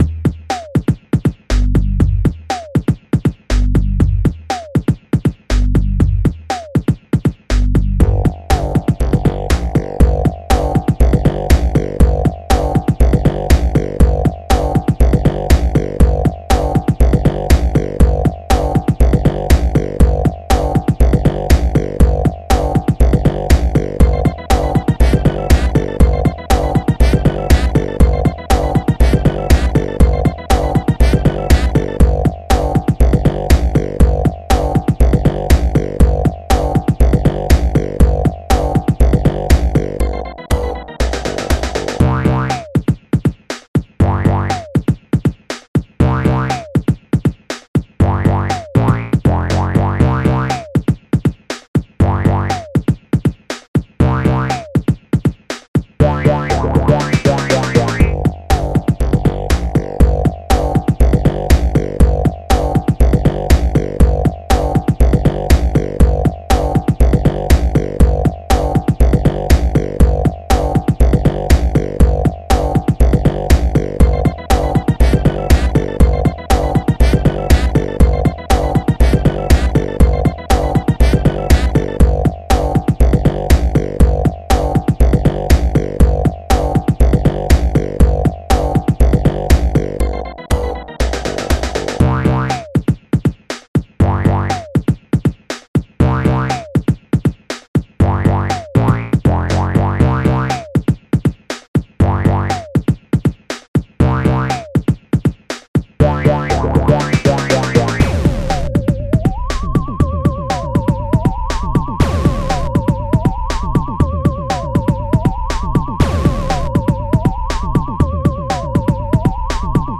Instrumental - Lyrics